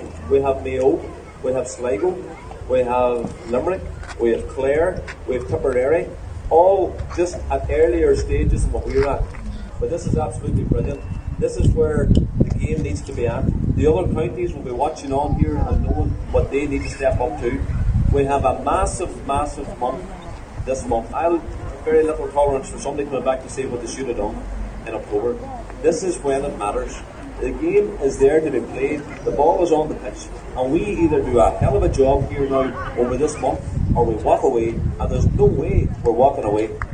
The demonstration began outside the county council offices in Lifford and then moved through the town, and as a number of mica campaigners took to the stage to deliver speeches to the bustling crowd.